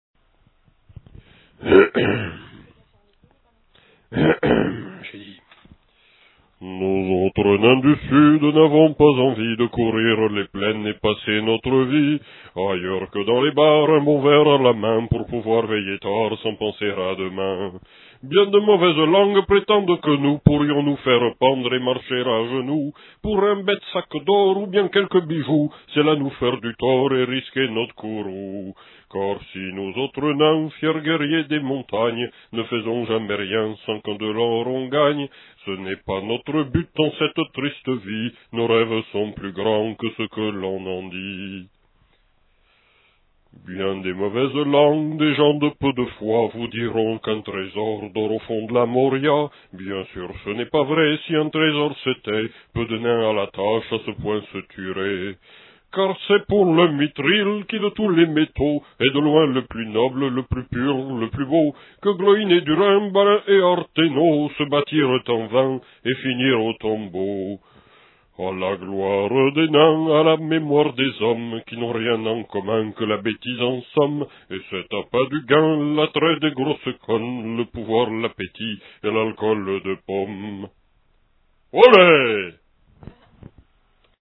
chant de nains.